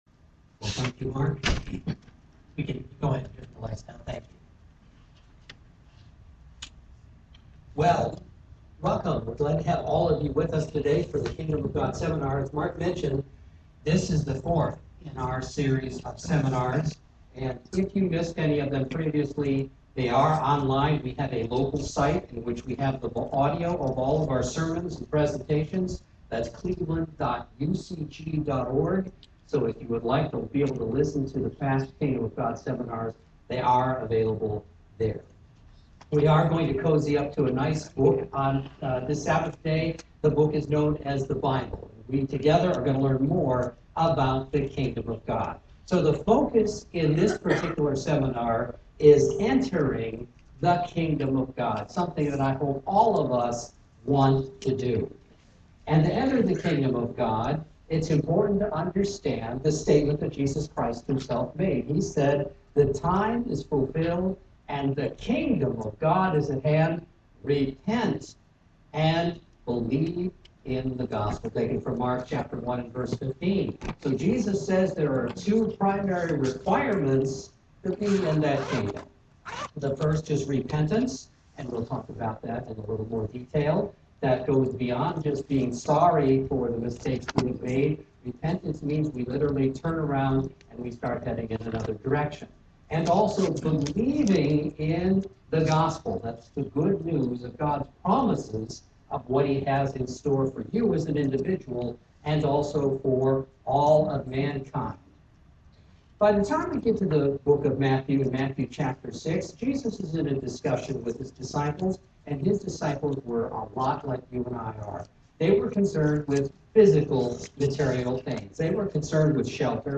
Learn about this plan in this Kingdom of God seminar.